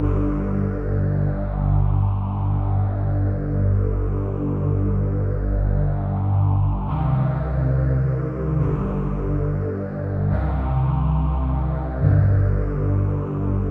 VTS1 Space Of Time Kit Melody & Synth
VTS1 Space Of Time Kit 140BPM Deep Pad DRY.wav